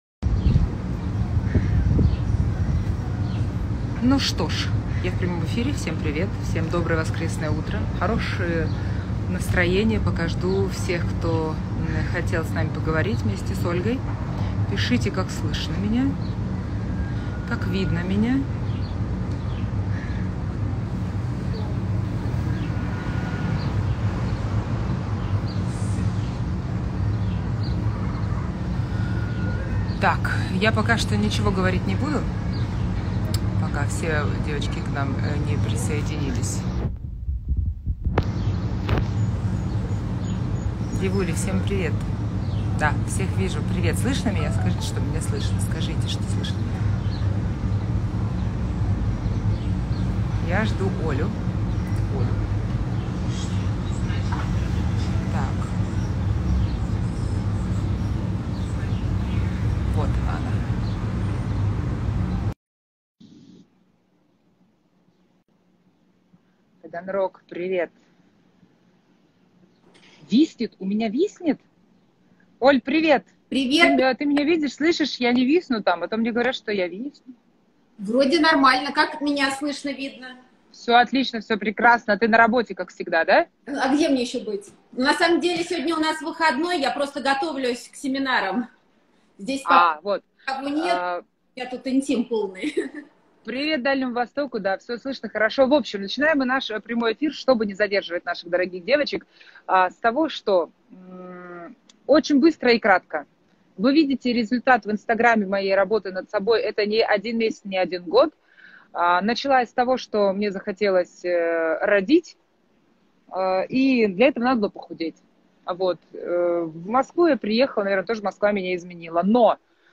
Персональное интервью